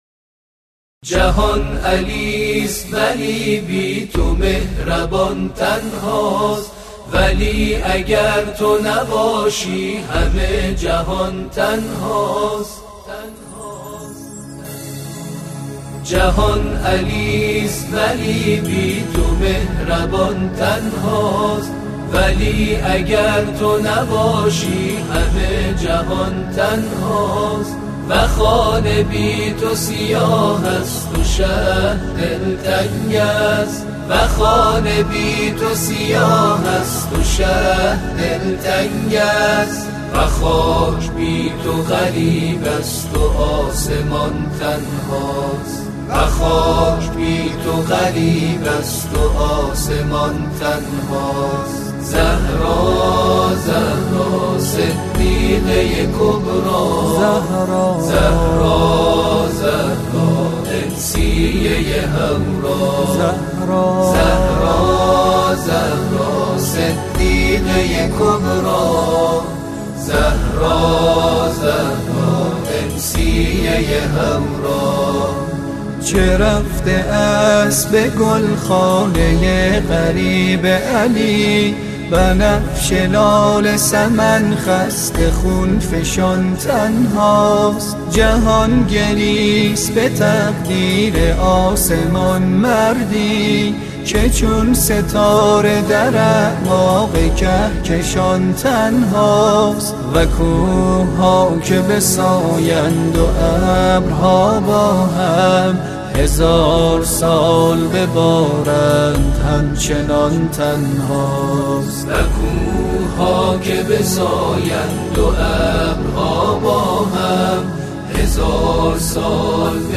دانلود تواشیح